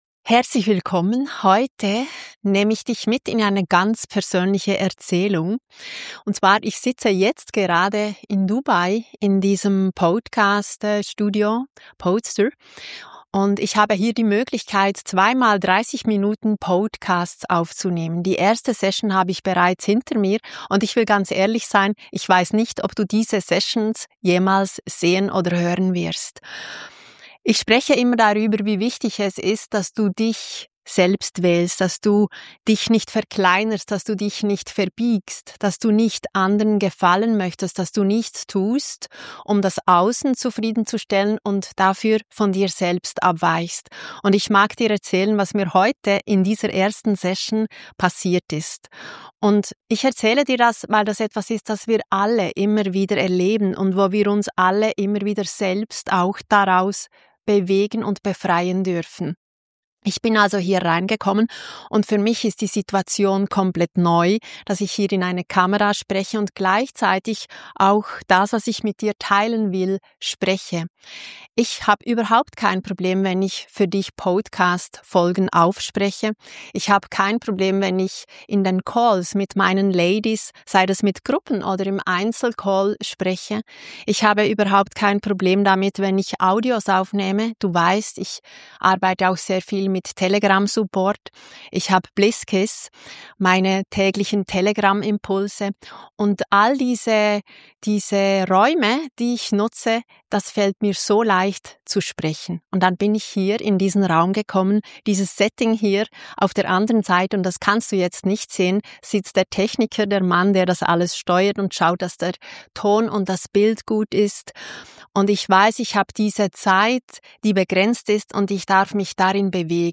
#38 Mitten durch das Feuer | Live aus dem Podcast Studio